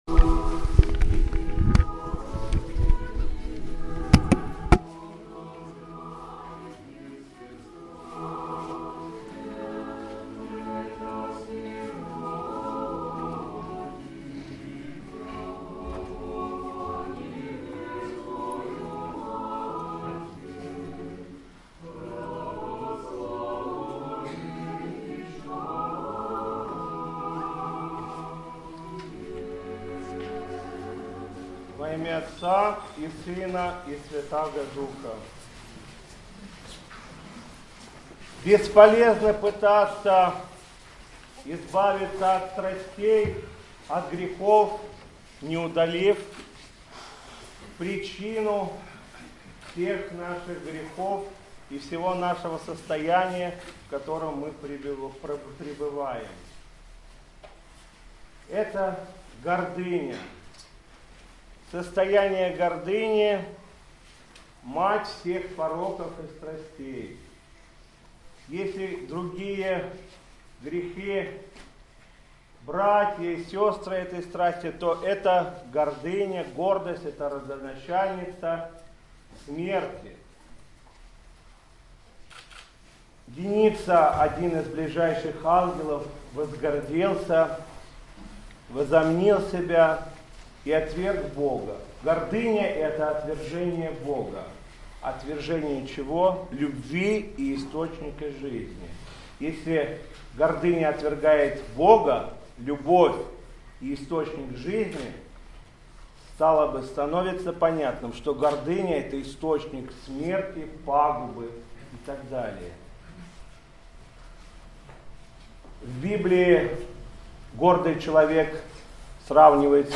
По окончании чтения канона митрополит Игнатий обратился к присутствующим с проповедью.
Митрополит Игнатий. Проповедь во вторник первой седмицы Великого поста